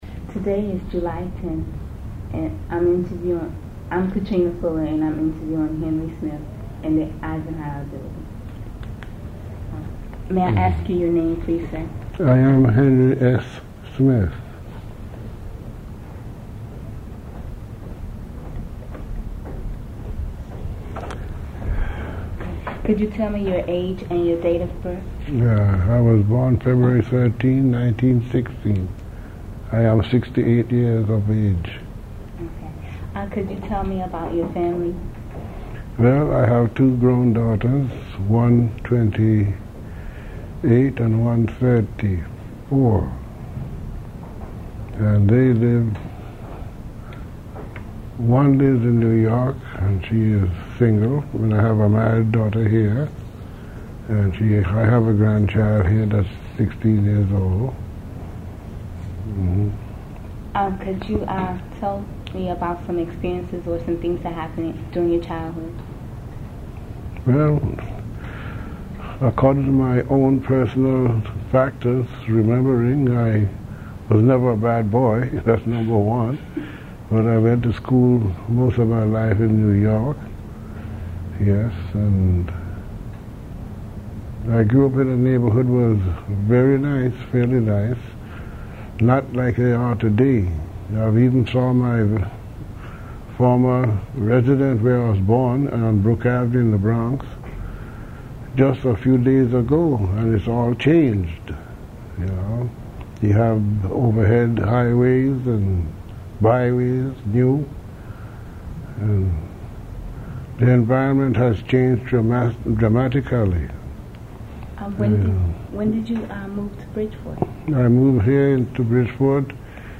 Bridgeport Living History : Oral Histories Conducted by Bridgeport Youth in 1984